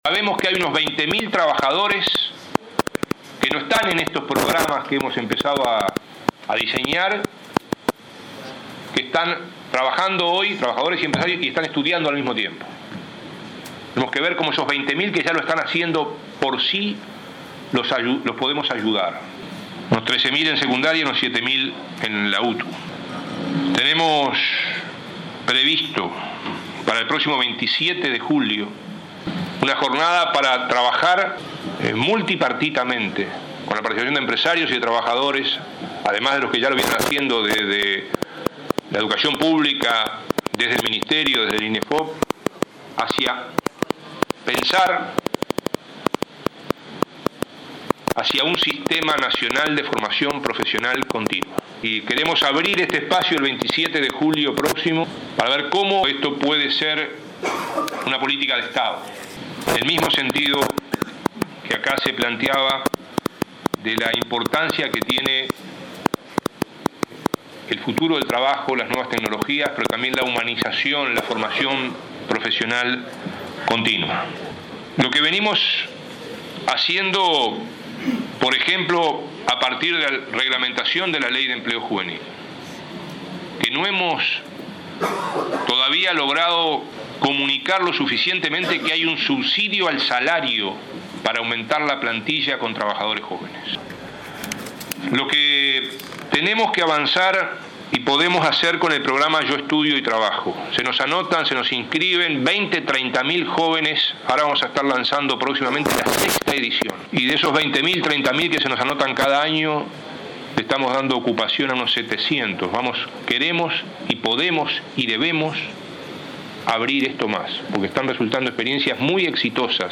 El Gobierno abrirá el 27 de julio una instancia de trabajo multipartito con el fin de elaborar un Plan Nacional de Formación Profesional Continua. Así lo informó el ministro Ernesto Murro en su disertación en Acde sobre Cultura del Trabajo. El jerarca subrayó que se quiere apoyar a unos 20 mil trabajadores que no están en los programas de capacitación existentes, pero que igual están trabajando y estudiando al mismo tiempo.